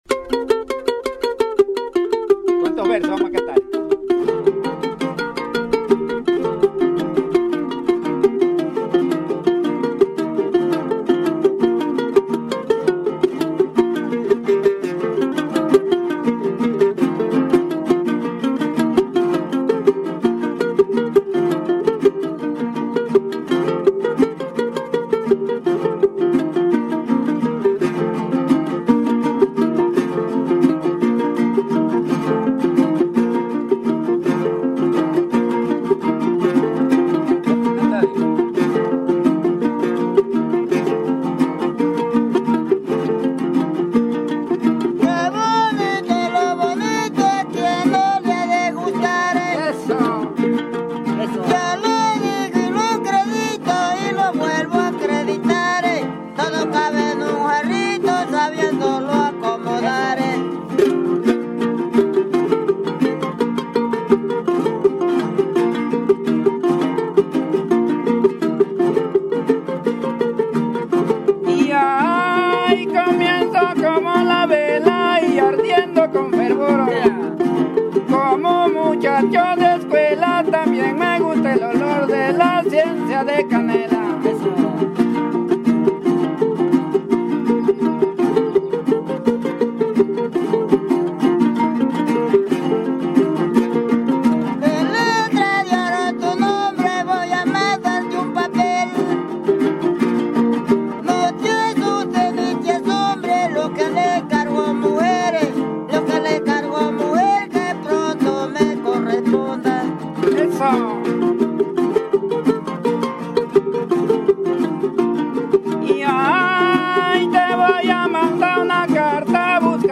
Músicos jaraneros de Santiago
guitarra de son y voz
jarana
Del cerro vienen bajando                                   Son jarocho de Los Tuxtlas, volumen II. Grabaciones de campo